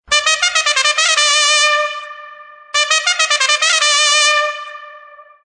Sonido de trompetas.
Trompetas
tonosmovil.net_trompetas.mp3